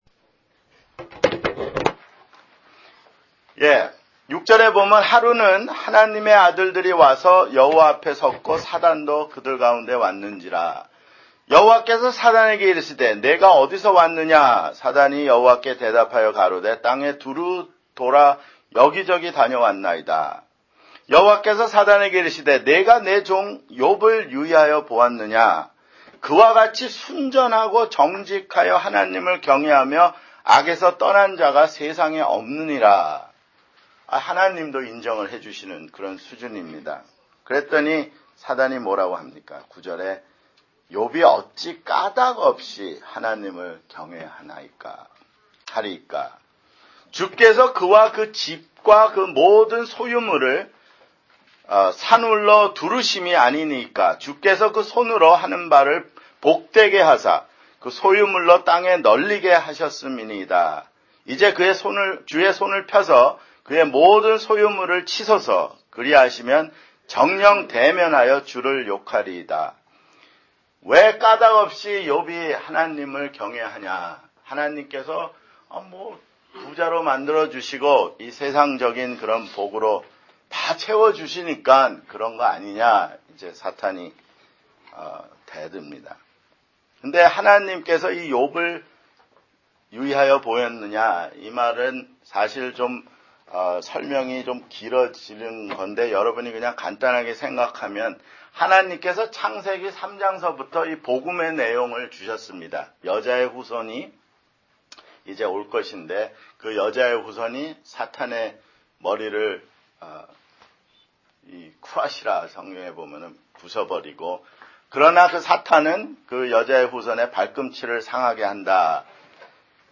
[주일 성경공부] 욥기